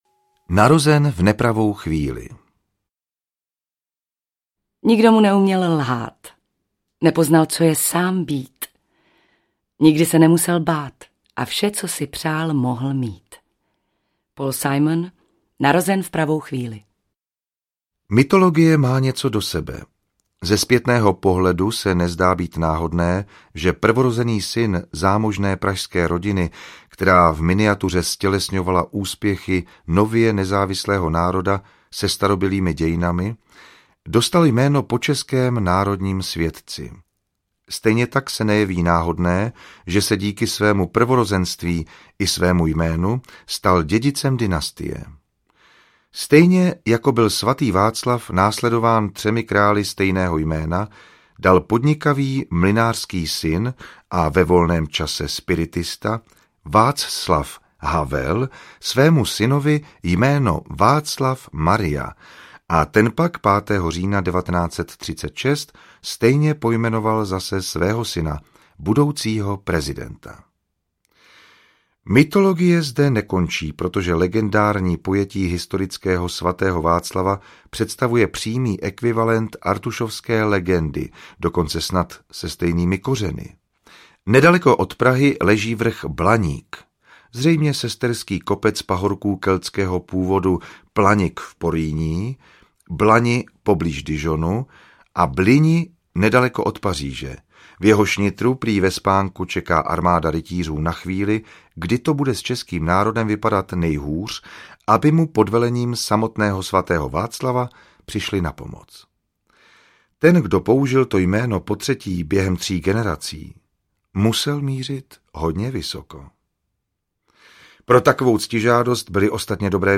Havel audiokniha
Ukázka z knihy